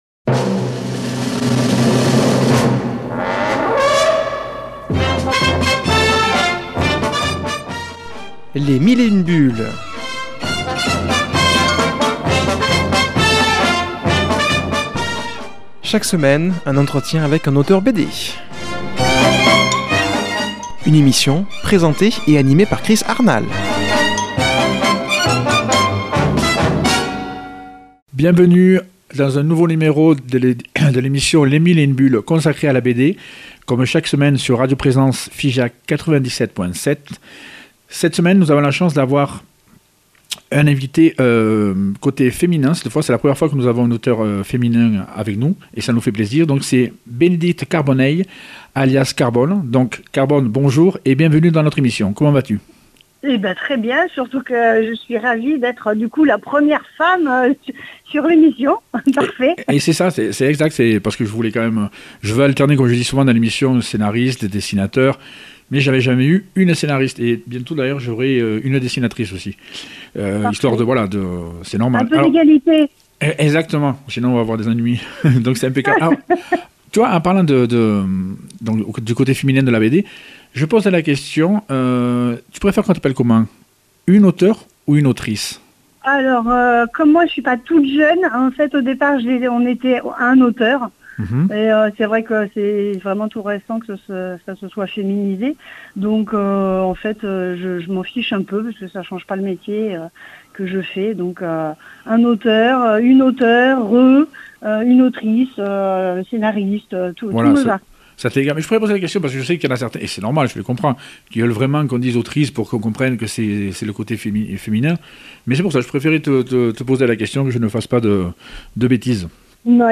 reçoit par téléphone